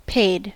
Ääntäminen
IPA : /ˈpeɪd/